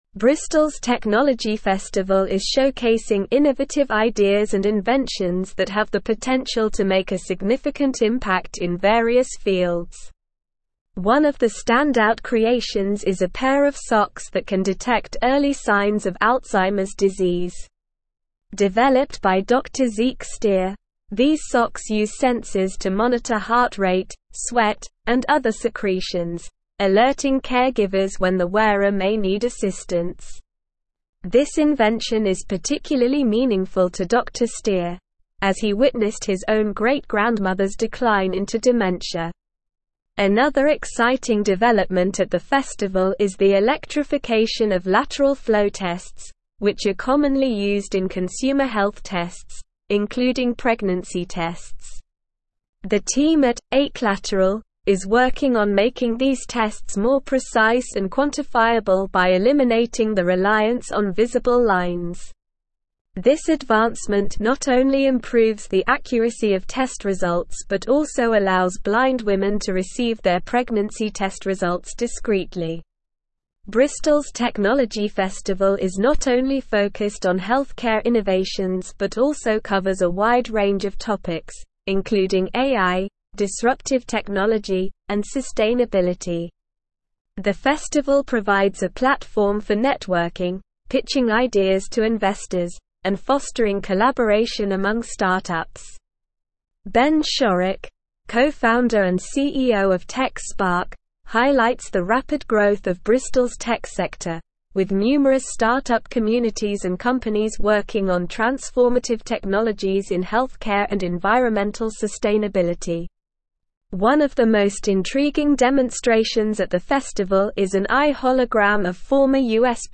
Slow
English-Newsroom-Advanced-SLOW-Reading-Bristols-Tech-Festival-Showcases-Innovative-Ideas-and-Inventions.mp3